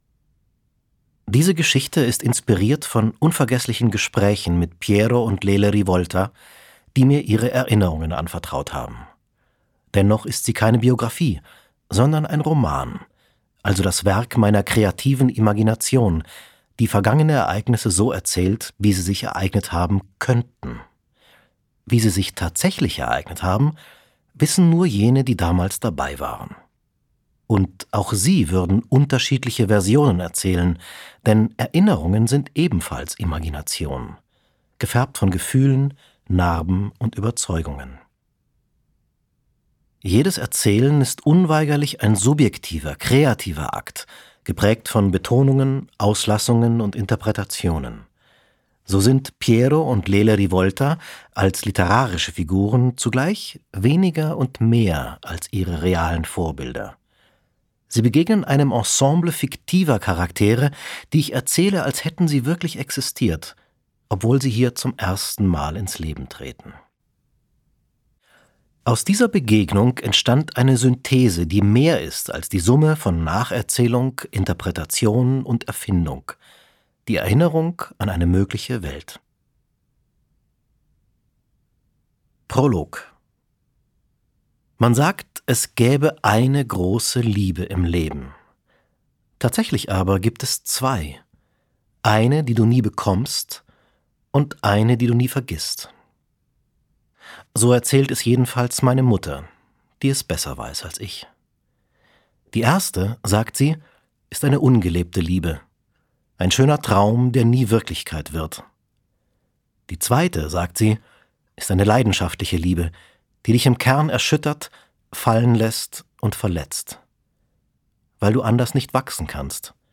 Villa Rivolta Gelesen von: Daniel Speck
• Sprecher:innen: Daniel Speck